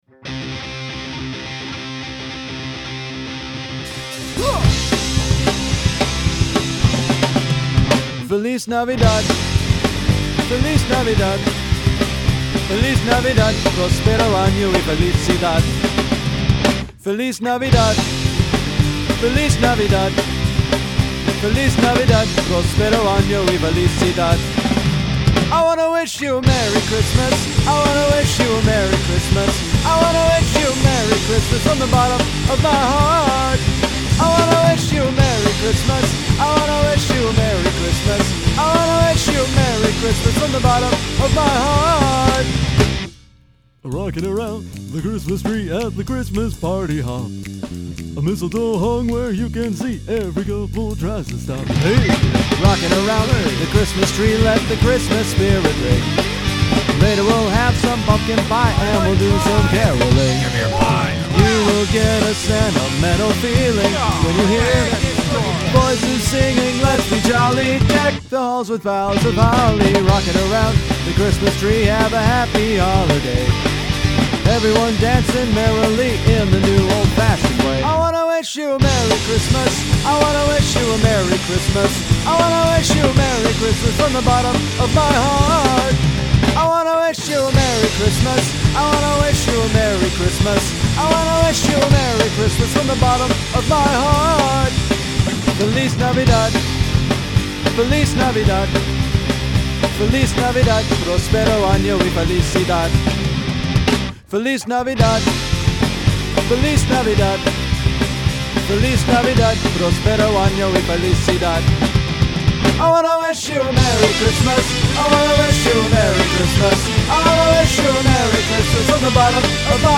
But that’s not enough to carry a punk tune like this!